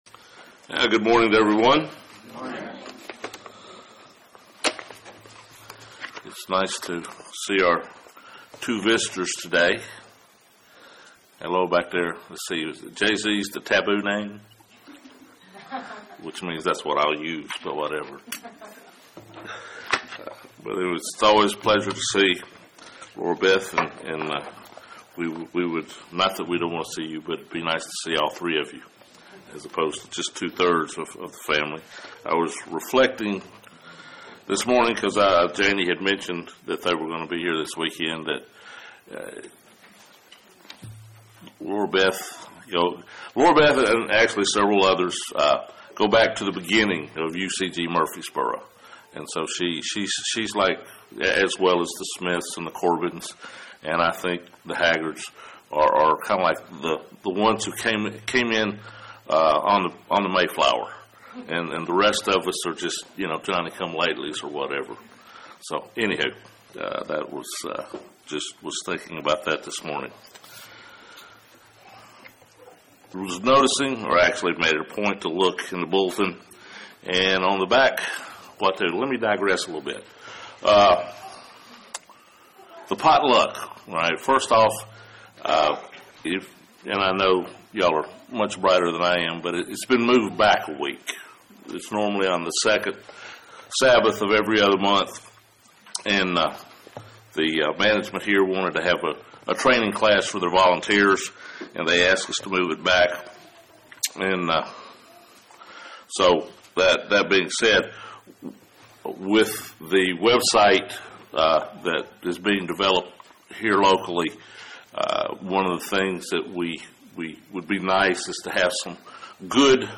This sermon discusses worthiness and self-examination as each of these concepts relate to the Passover.